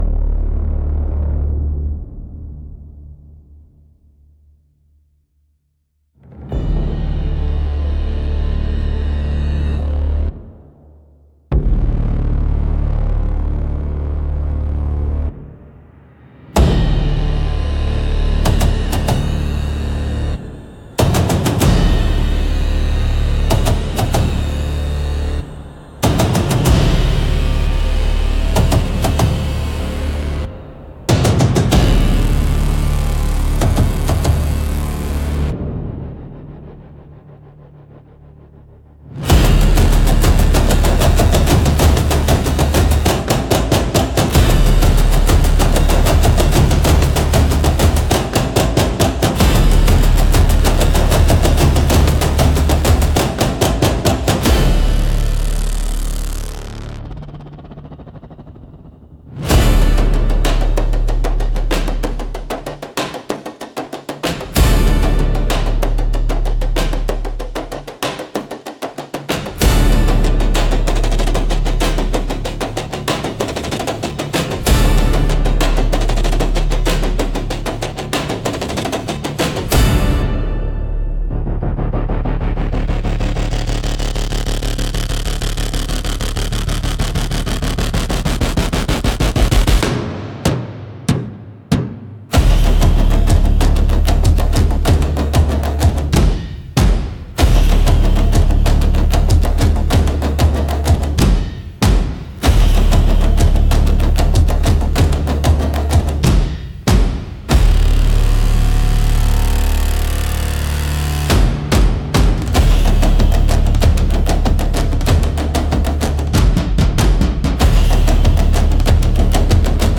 Instrumental - Storm caller -2.15